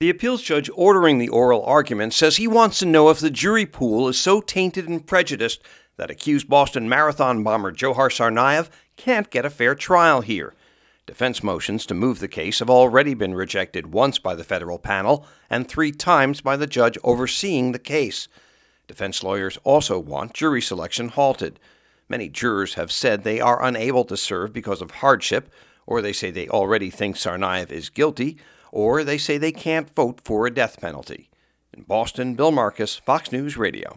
3PM NEWSCAST –